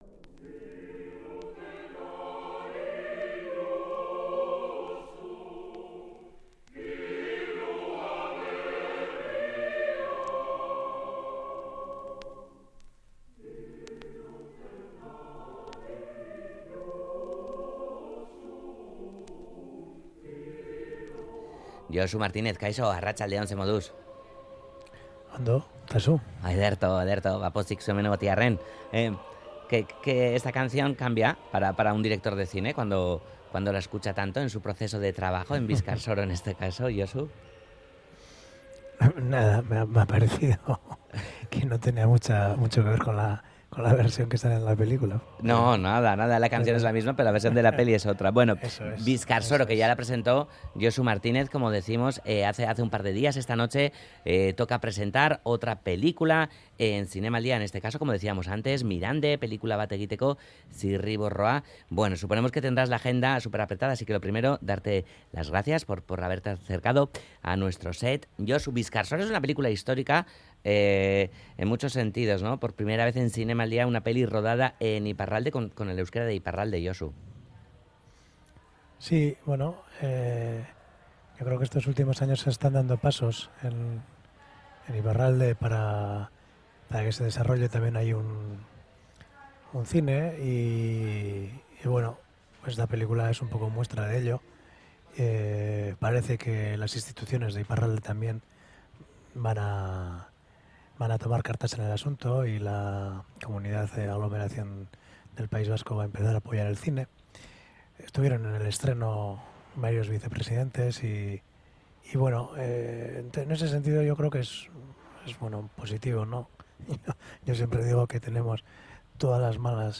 desde nuestro set en el Kursaal